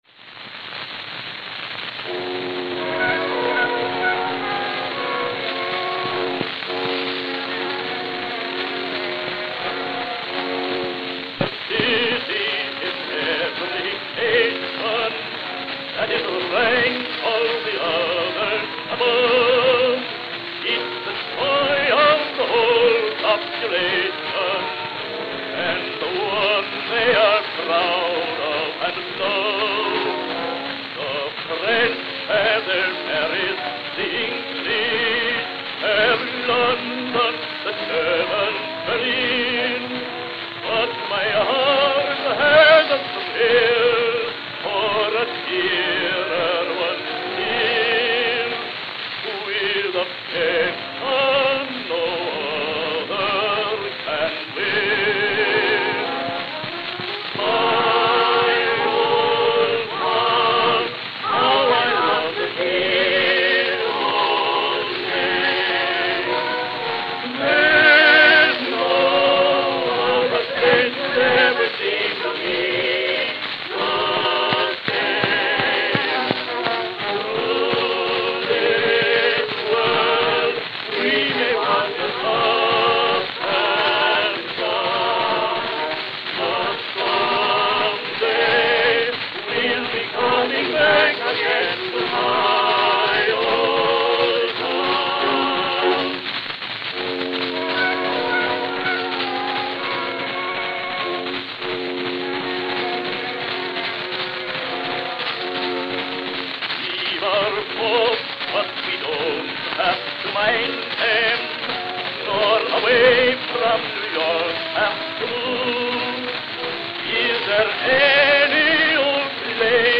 Note: Worn, skip at 0:11.